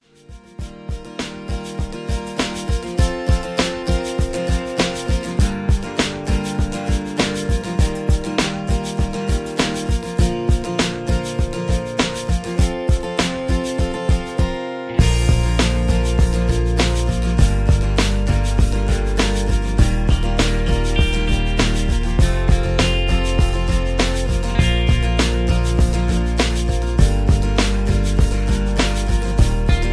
Key-Bb